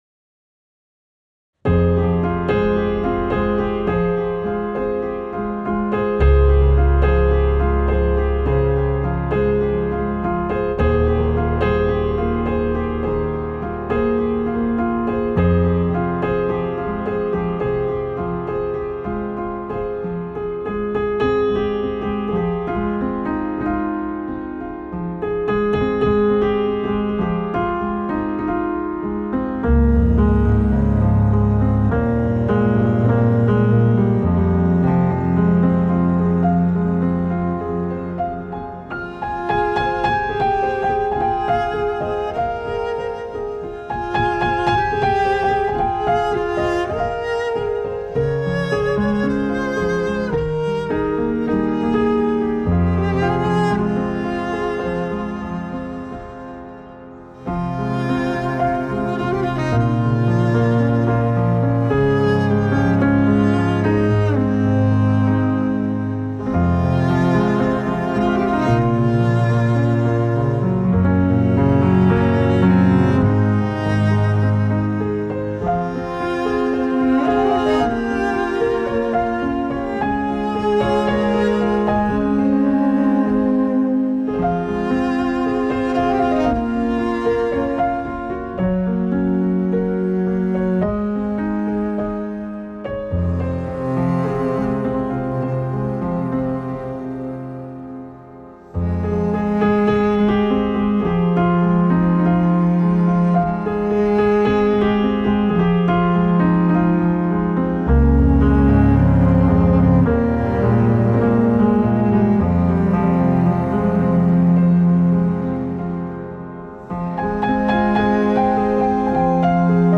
سبک آرامش بخش , پیانو , موسیقی بی کلام